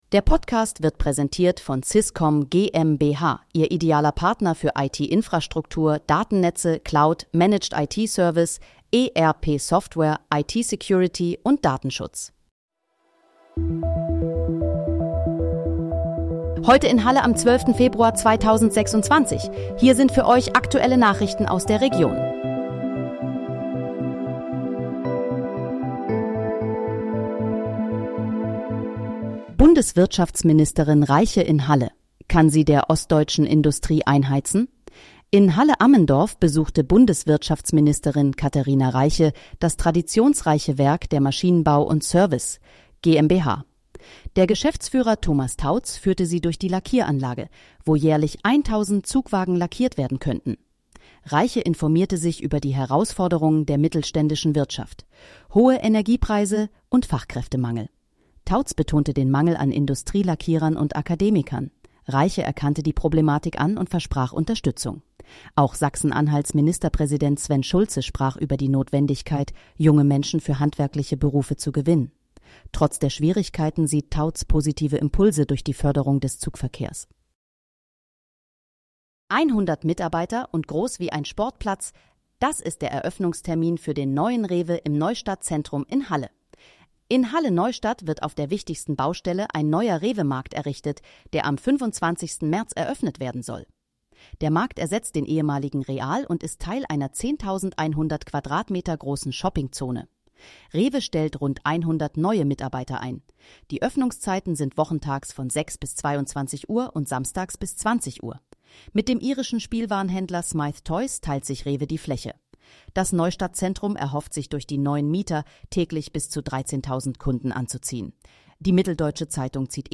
Heute in, Halle: Aktuelle Nachrichten vom 12.02.2026, erstellt mit KI-Unterstützung
Nachrichten